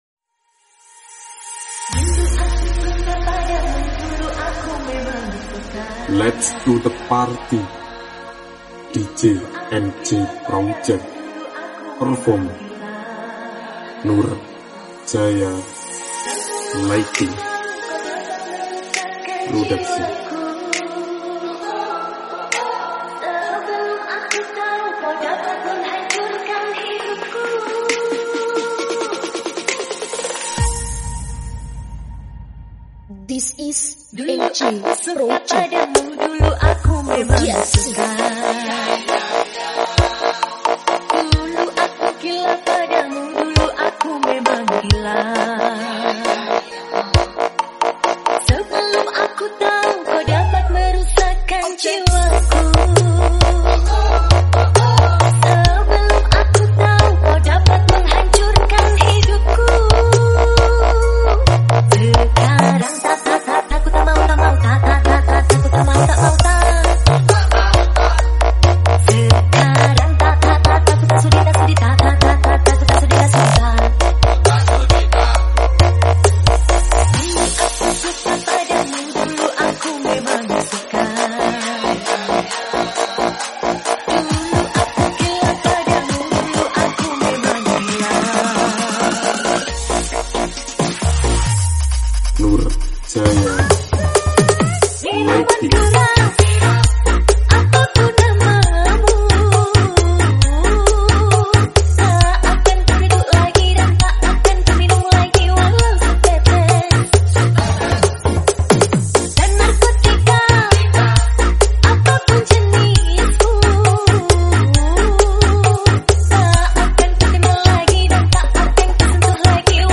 dangdut full battle bass